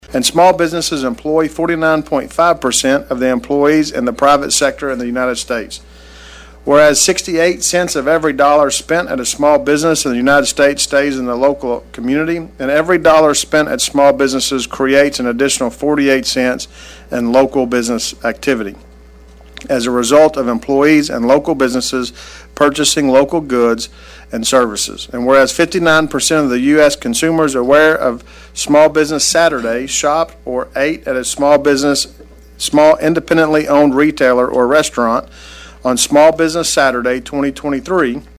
During last week’s City Council meeting, Mayor Cotton issued a proclamation to honor local small businesses and their significant contributions to the local economy and community.